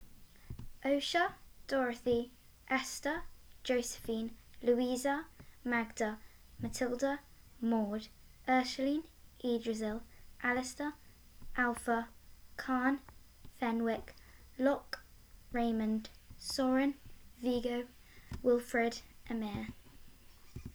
Aase - Oh-suh
Maude - Mord
Ursuline - Urgh-shuh-lean
Yggdrasil - Ee-druh-zill
Ymir - Em-ear